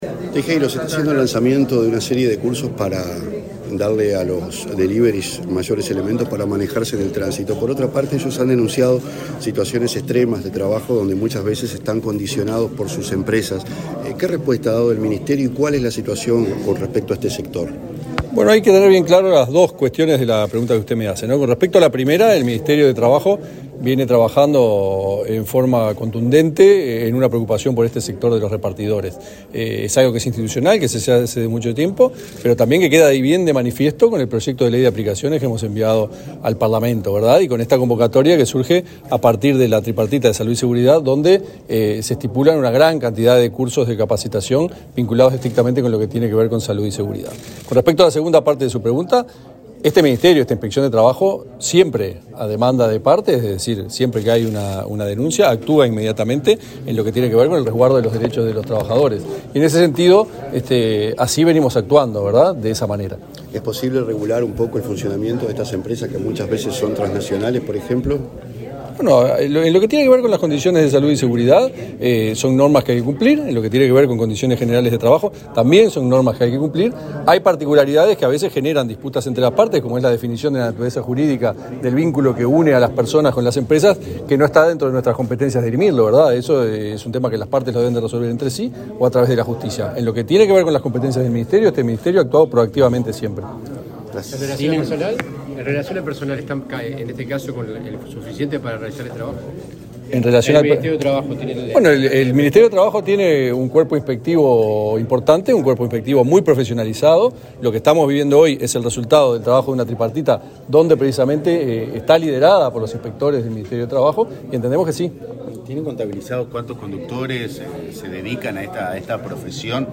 Declaraciones del inspector general de Trabajo, Tomás Teijeiro
El inspector general de Trabajo, Tomás Teijeiro; el director de la Unidad Nacional de Seguridad Vial (Unasev), Mauricio Viera, y el director general del Instituto Nacional de Empleo y Formación Profesional (Inefop), Pablo Darscht, participaron en el lanzamiento de capacitaciones sobre seguridad vial para repartidores en motocicleta y bicicleta. Luego Teijeiro dialogó con la prensa.